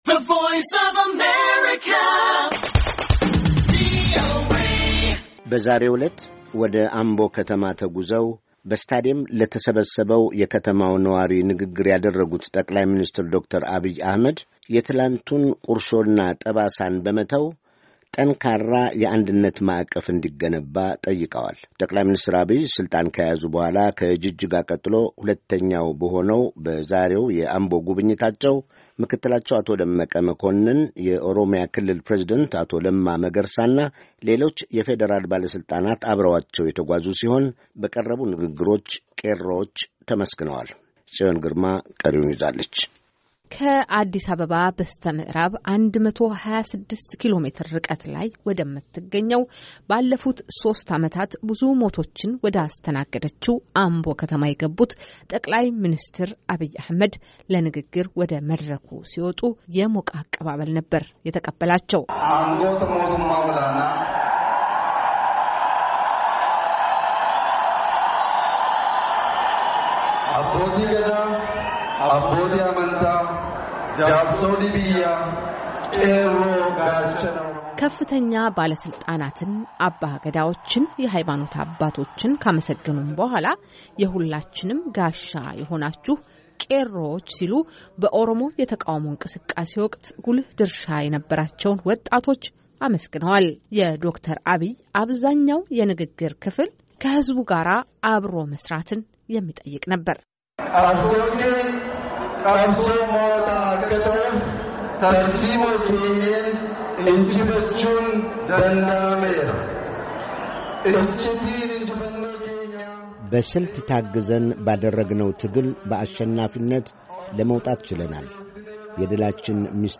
በዛሬው ዕለት ወደ አምቦ ከተማ ተጉዘው በስተዲየም ለተሰባሰው የከተማው ነዋሪ ንግግር ያደረጉት ጠቅላይ ሚኒስትር ዶ/ር አብይ አህመድ የትናንቱን ቁርሾና ጠባሳን በመተው ጠንካራ የአንድነት ማዕቀፍ እንዲገነባ ጠይቀዋል።